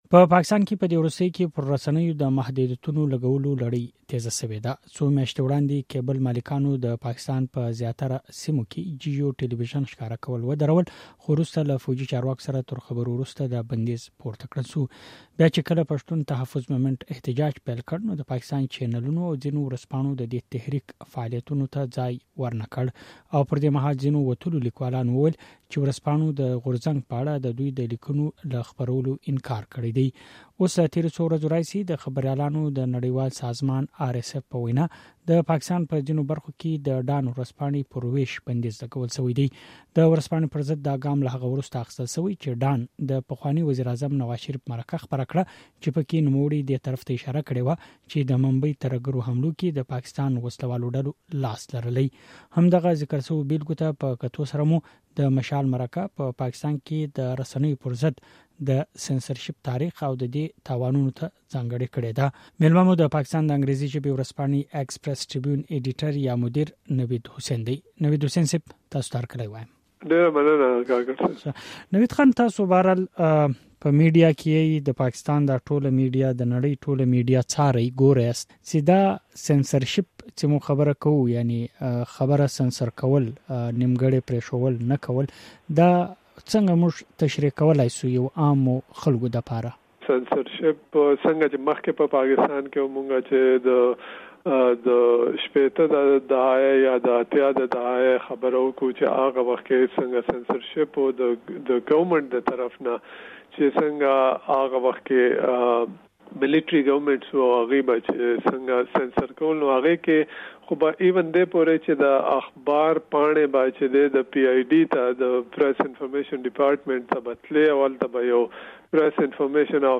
د مشال مرکې پاڼې ته ښه راغلاست.